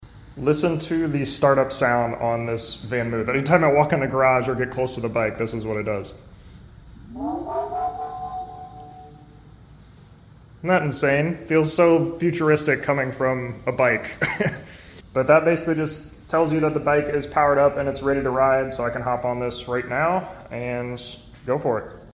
Futuristic Start up sound!